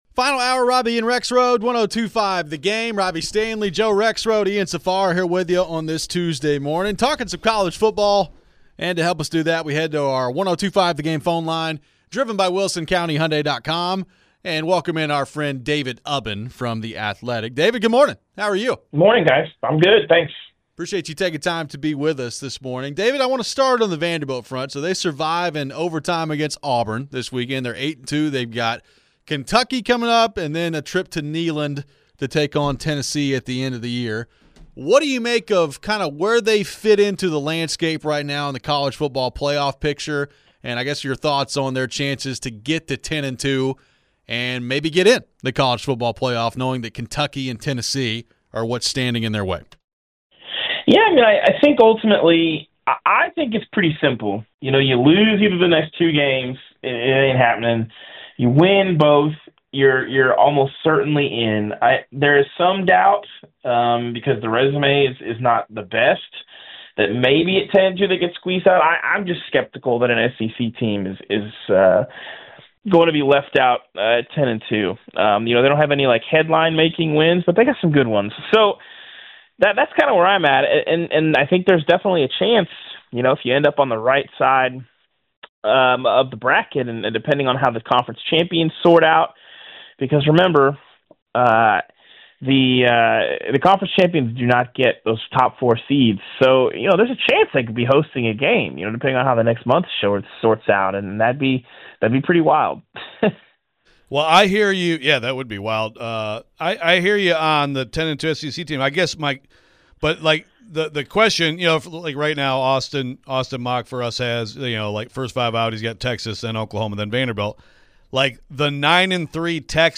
Interview
What should Vandy be rooting for to make the playoff? We continue some of the Vandy talk and take your phones.